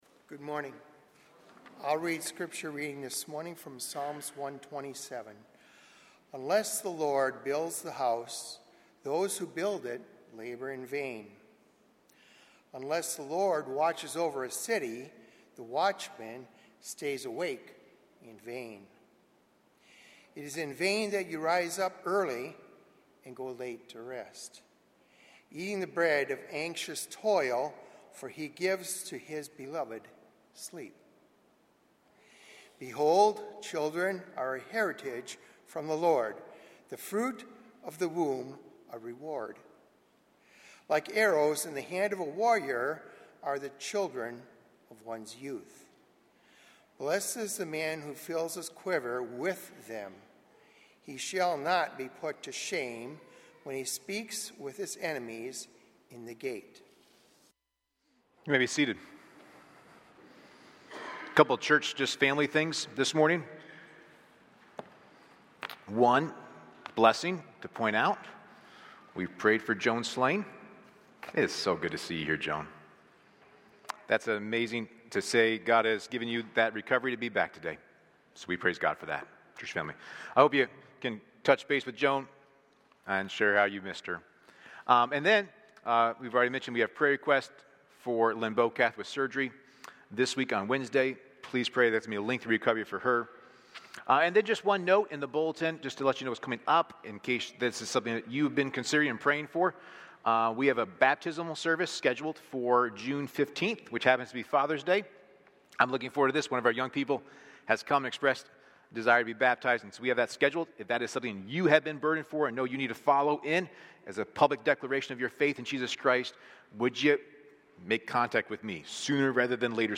A sermon from the series "Individual Sermons."